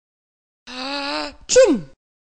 Atchoum
Hatschi
atchoum.mp3